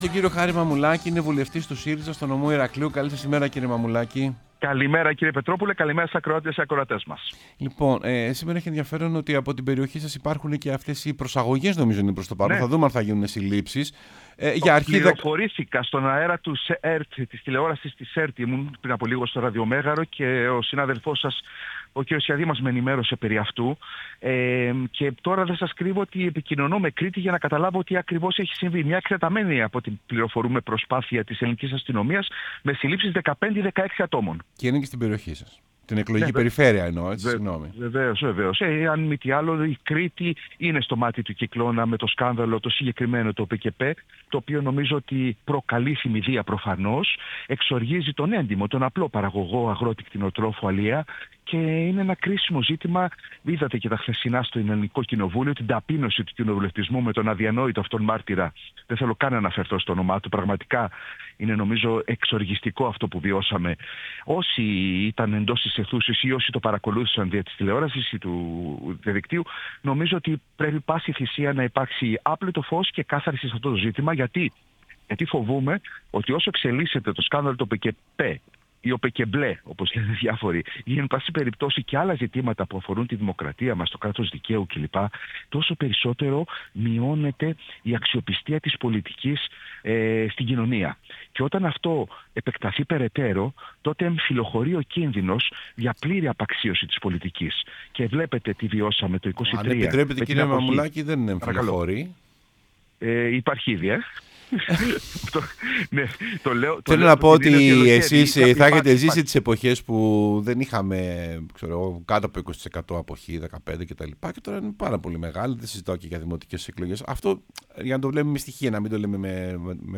Ο Χάρης Μαμουλάκης, βουλευτής ΣΥΡΙΖΑ μίλησε στην εκπομπή Σεμνά και ταπεινά